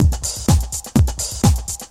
Straight / 125,918 / 1 mes